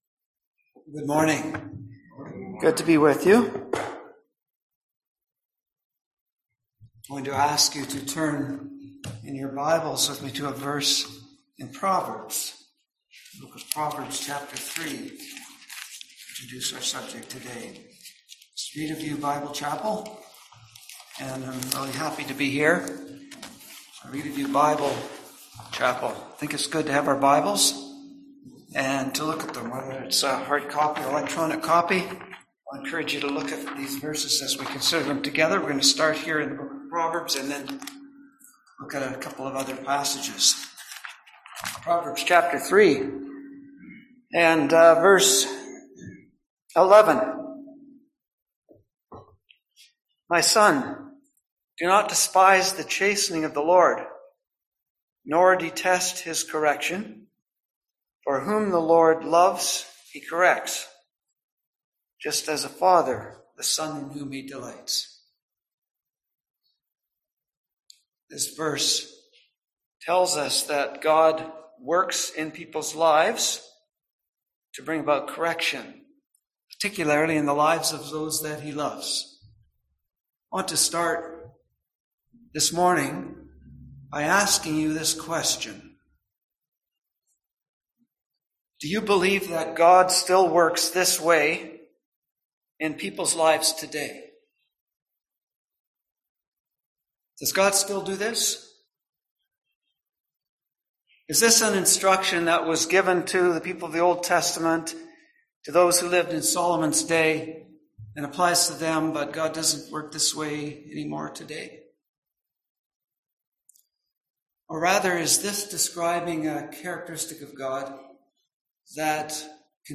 Passage: Amos 4:1-11, 2 Kings 13, Proverbs 3:11-12 Service Type: Sunday AM Topics: Discipline